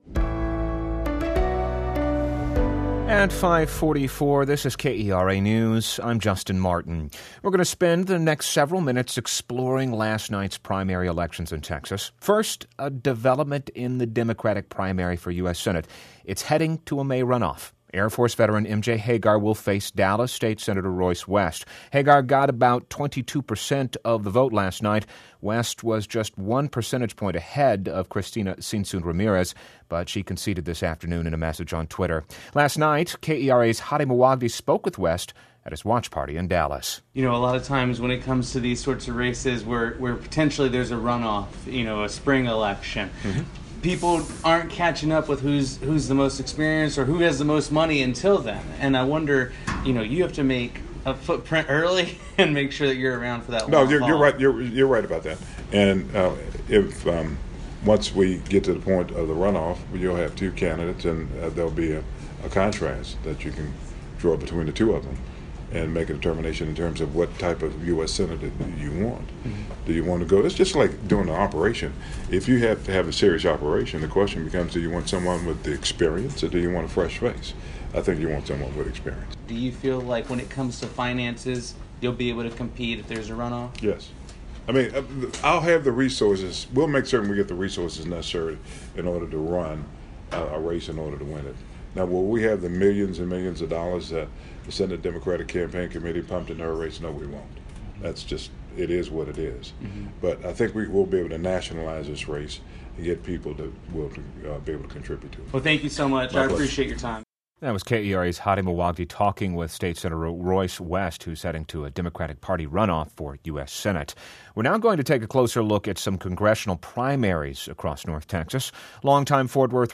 election_special_kera_march_4.mp3